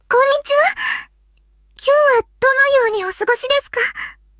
audio_parler_tts_japanese_out_sample_1.wav